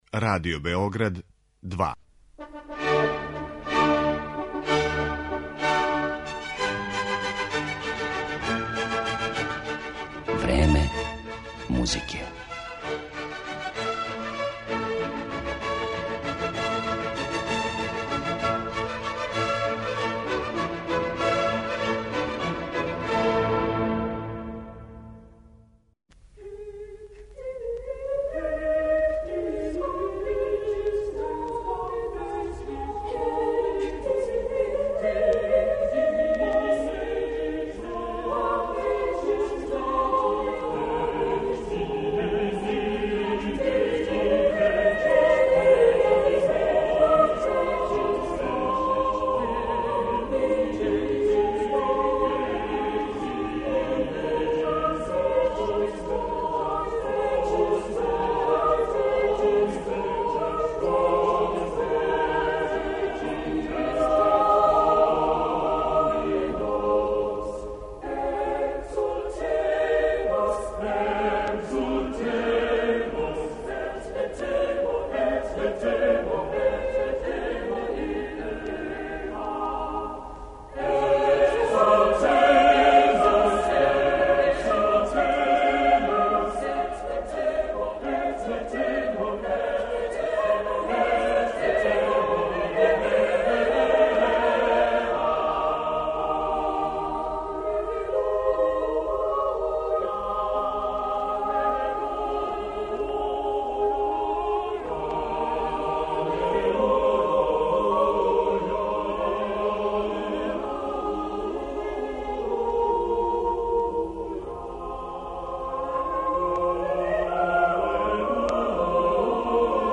Овог истакнутог британског диригента и оргуљаша слушаћете претежно на челу ансамбла са којим је досегао врхунце у каријери и како изводи дела Вилијама Берда, Хајнриха Шица, Ђованија Пјерлуиђија да Палестрине, Јохана Себастијана Баха и Бенџамина Бритна.